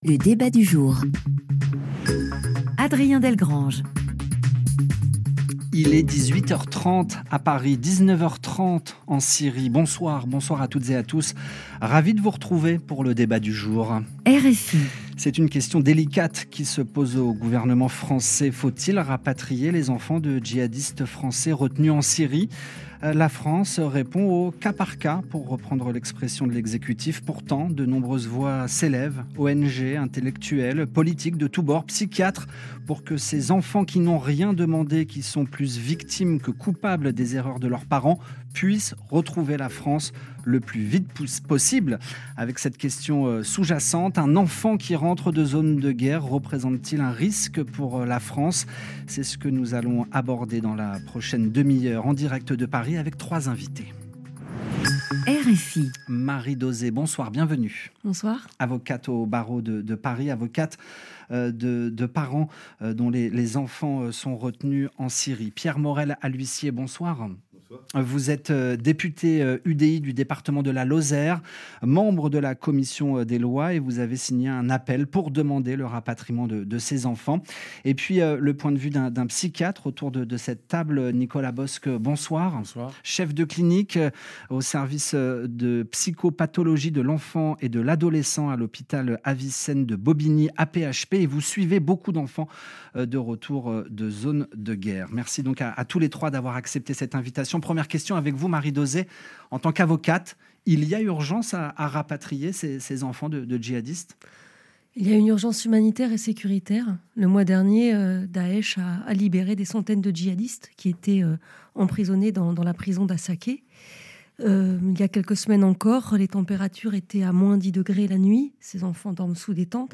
diffusé sur RFI le 23 février 2022